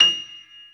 55p-pno38-F6.wav